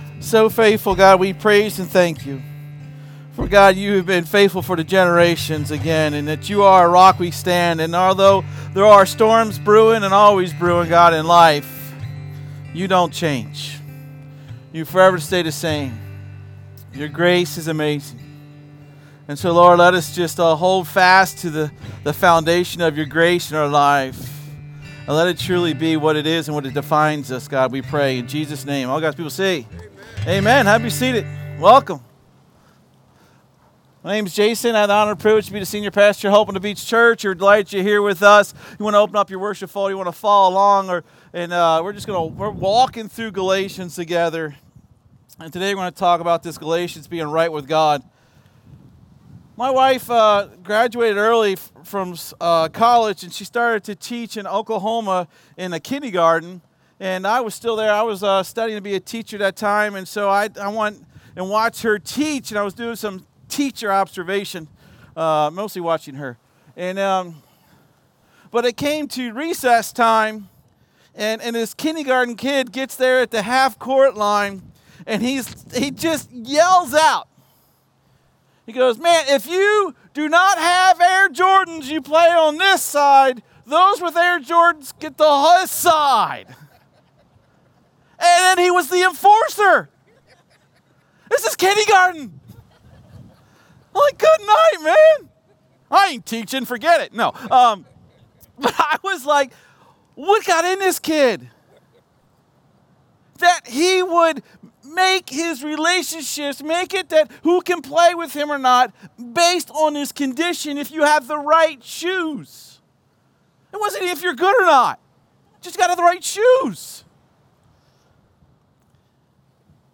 SERMON DESCRIPTION Chapter 2 of The Apostle Paul's letter is particularly significant as it addresses a brewing controversy within our church.